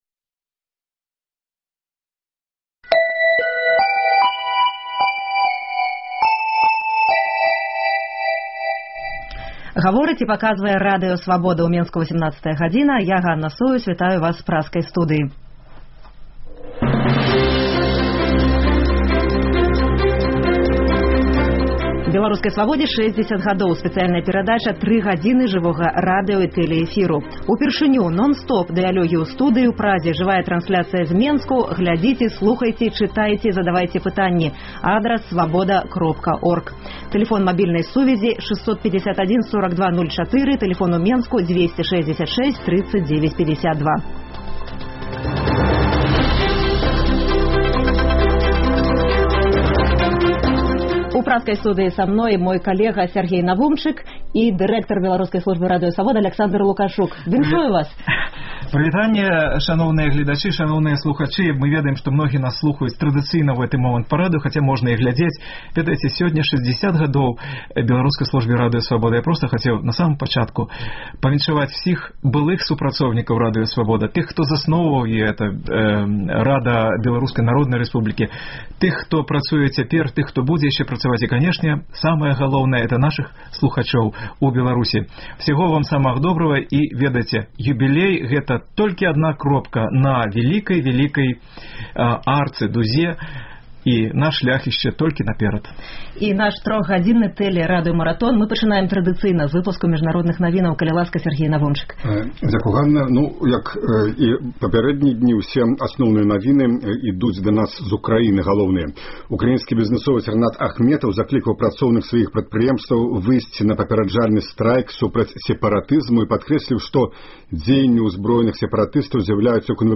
20 траўня Свабода адзначае 60-годзьдзе ў жывым TV-эфіры.